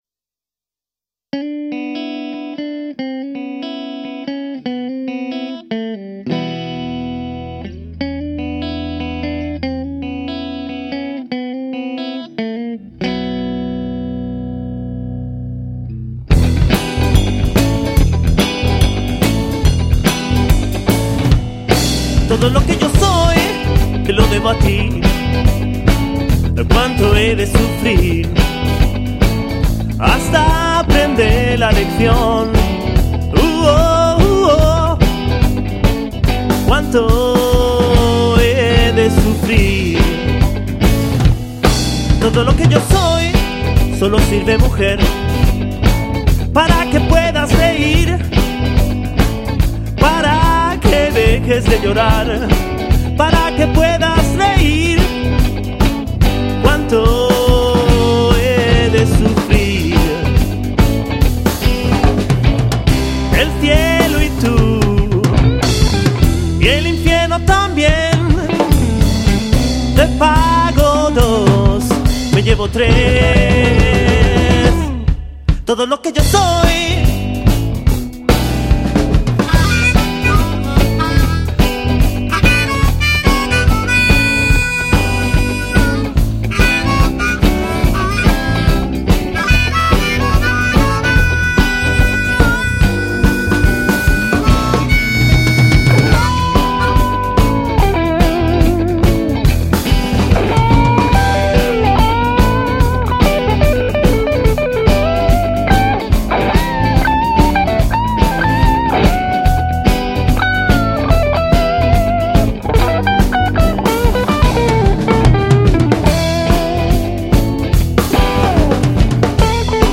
Blues Band en quinteto
Guitarra solista
Voz solista y guitarra
Batería
Armónica, teclado, voz y coros
Bajo eléctrico